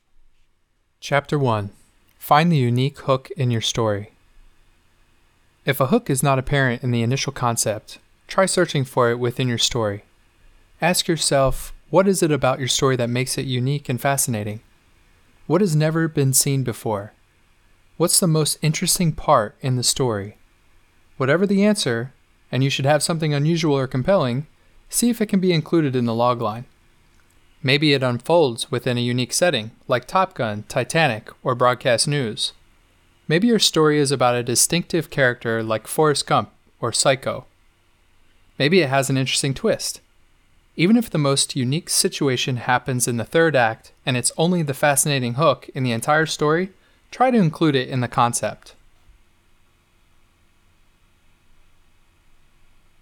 Audio Demos